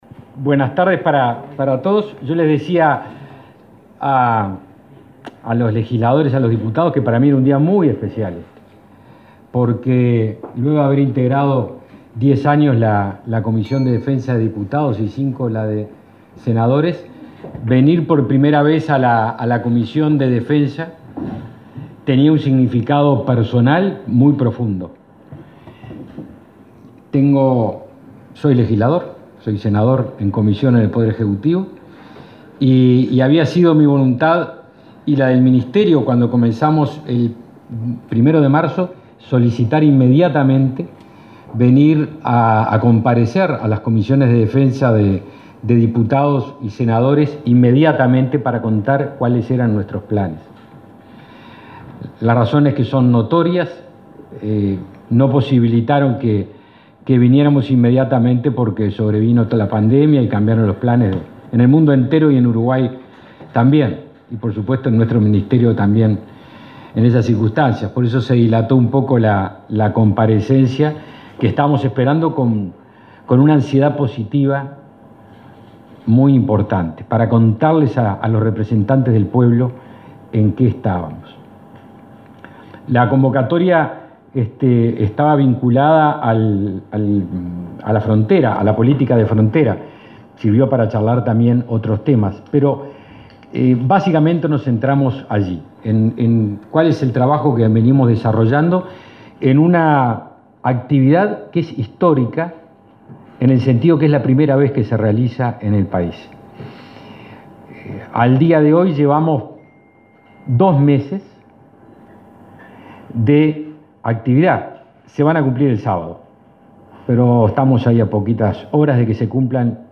En dos meses, las Fuerzas Armadas dedicaron 550.000 horas de servicio para efectuar 2.300 patrullajes en unos 800 puestos fijos de control, utilizados para la custodia de 35.000 kilómetros cuadrados. A esto se añadió el trabajo de prevención en el contexto de emergencia sanitaria, explicó el ministro de Defensa Nacional, Javier García, en su comparecencia en el Parlamento.